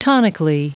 Prononciation du mot tonically en anglais (fichier audio)
Prononciation du mot : tonically